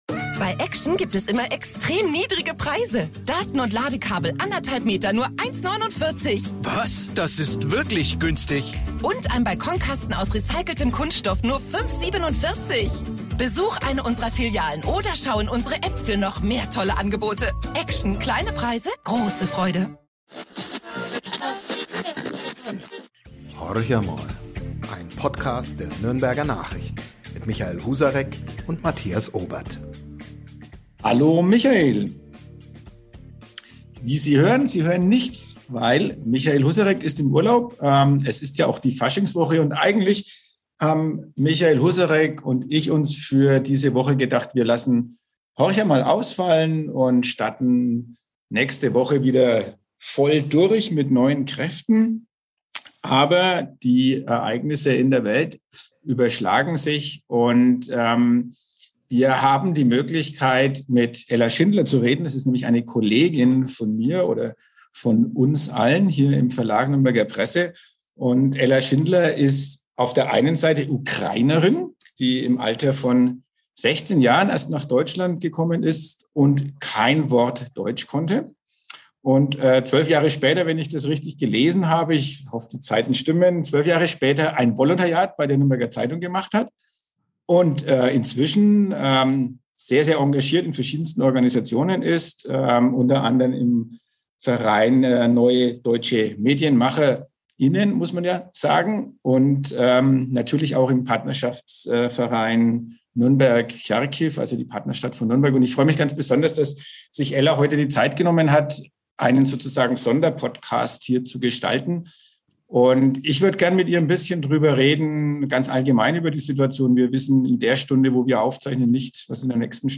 Sie spricht mit großer Bestürzung über die Lage in ihrem Heimatland und einen Krieg, der von Russland schon viel länger gegen die Ukraine geführt wurde. Die dramatische Situation der in Charkiw lebenden Menschen, schildern ihr die vielen Kontakte, die sie weiterhin dorthin hat. Und die Hilferufe werden von Tag zu Tag eindringlicher.